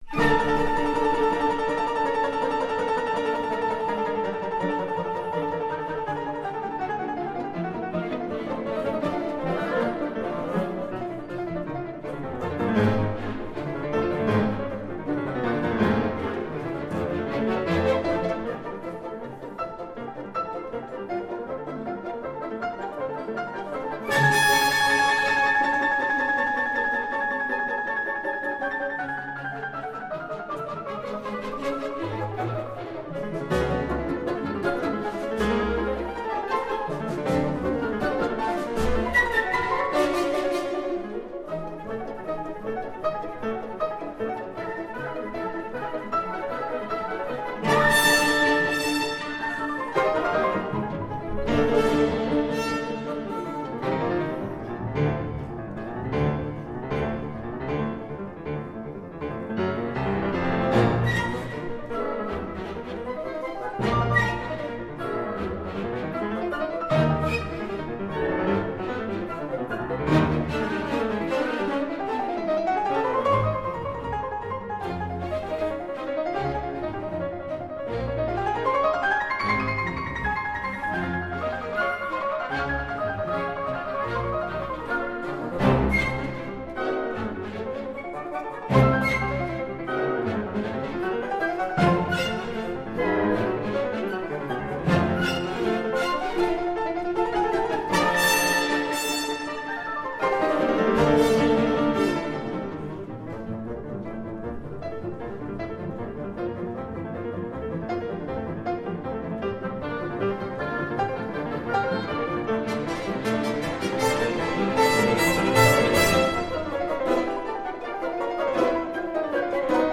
la scena si svolge davanti a una pizza, in un rumoroso ristorante italiano.